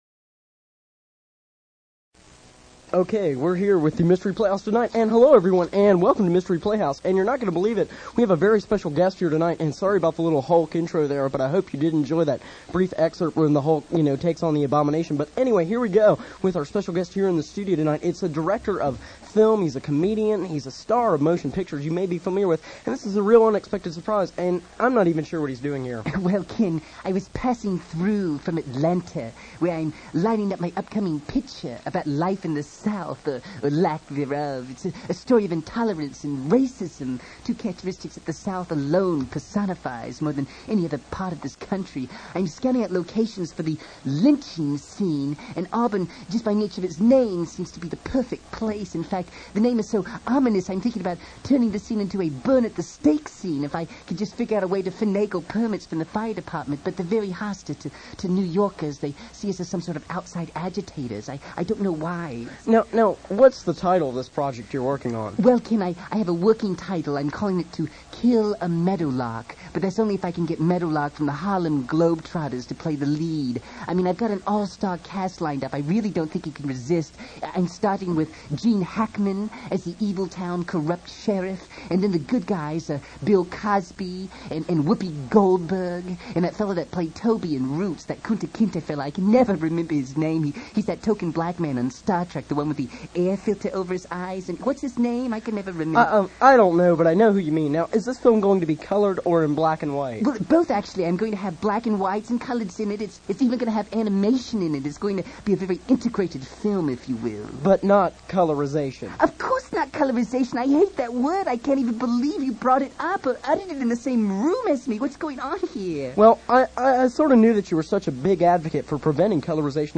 All these recordings were made at WEGL, Auburn University.
- Various comedic show intros -